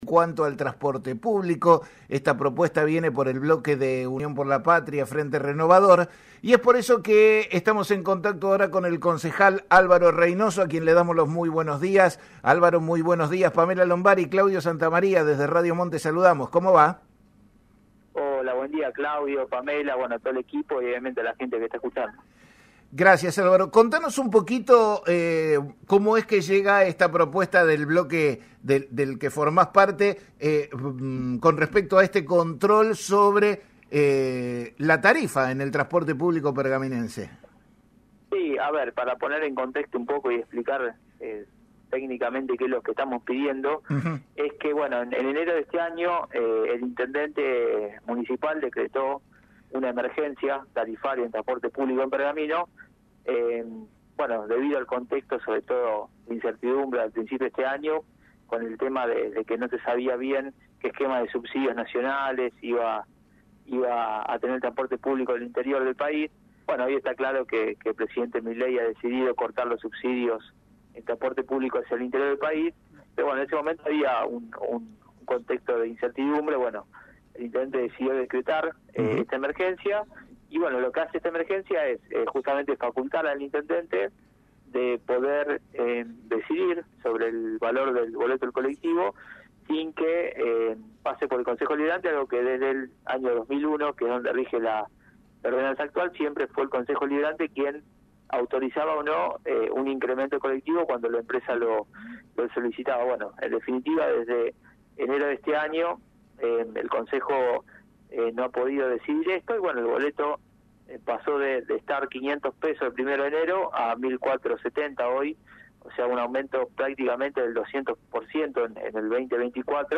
En una entrevista exclusiva con el programa «La mañana de la radio» de Radio Mon Pergamino, el concejal Álvaro Reynoso del bloque Unión por la Patria, se pronunció sobre la propuesta presentada por su bloque en relación al control de las tarifas del transporte público en Pergamino.